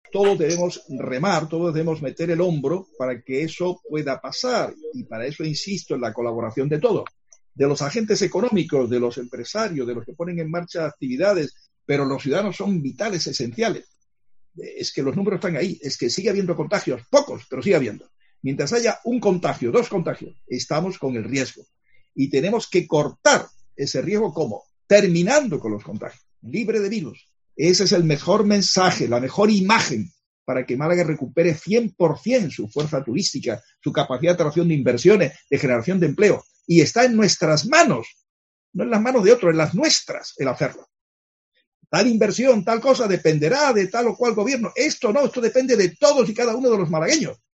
El alcalde se refiere en rueda de prensa a la fase 3.